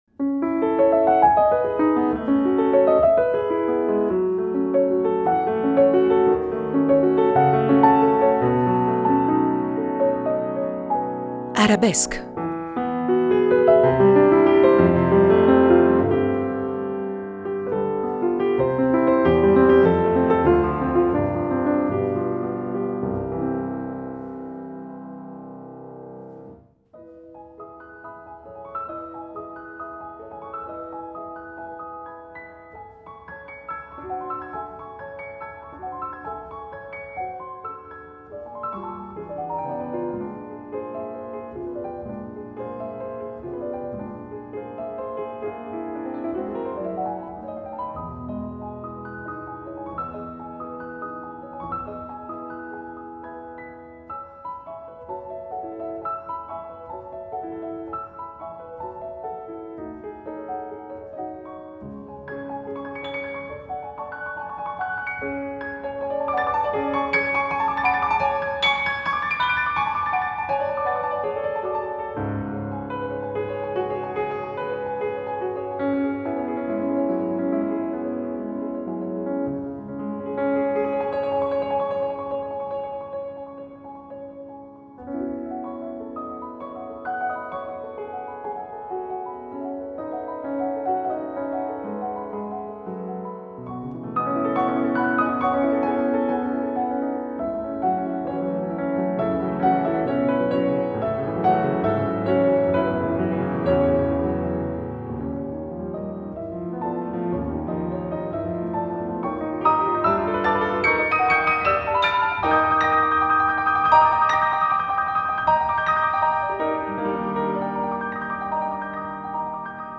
La registrazione della conversazione ‒ punteggiata da pagine di Ravel, Chopin e Saint-Saëns ‒ è stata divisa in due parti per poterla riproporre in Arabesque